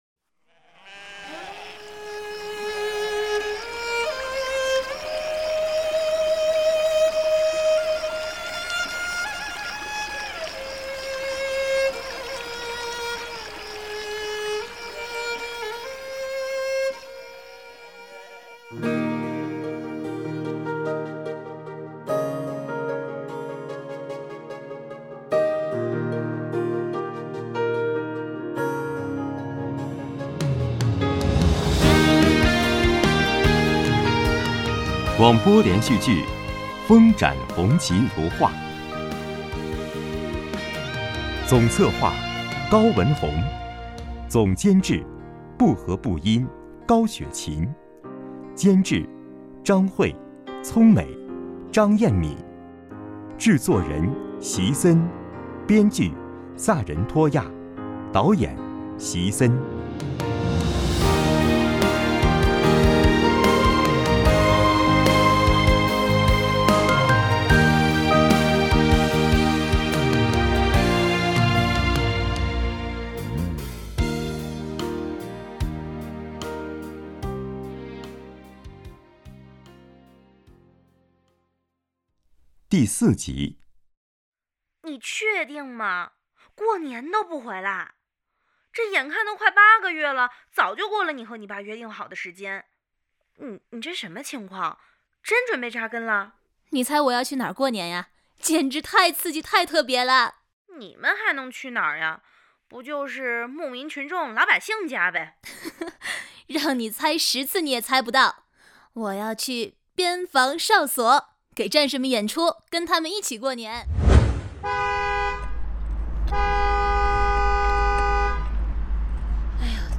广播类型：连续剧